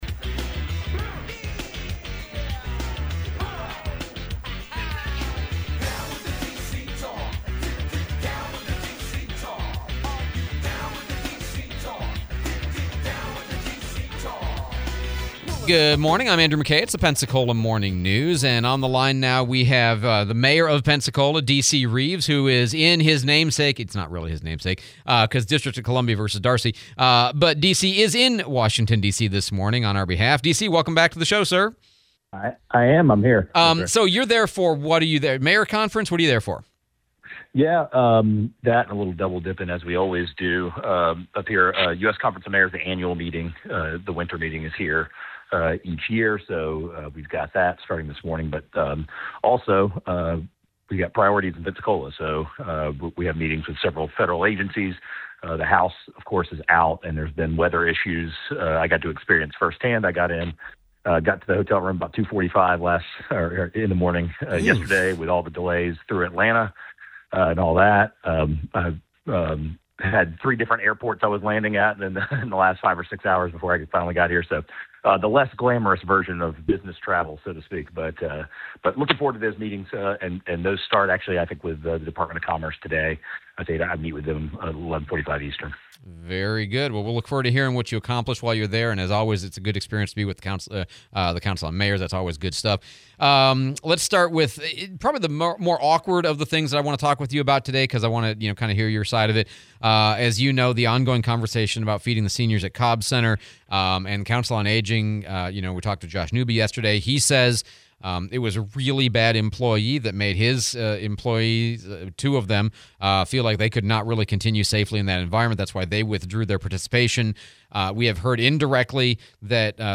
01/28/26 DC Reeves interview